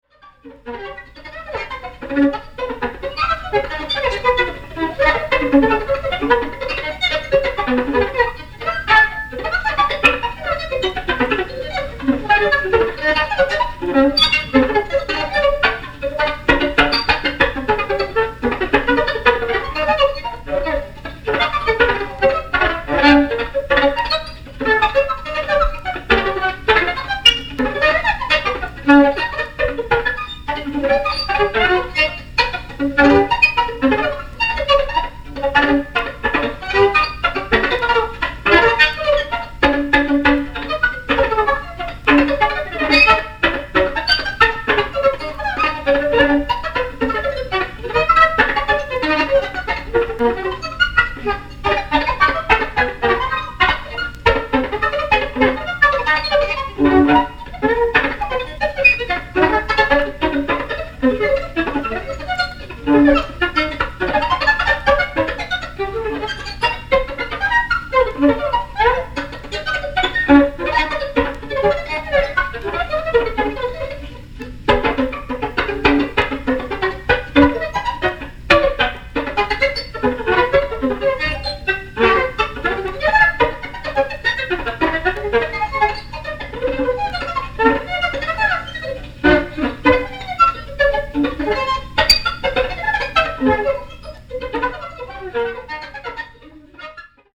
キーワード：即興　エレクトロアコースティック　宅録　ミニマル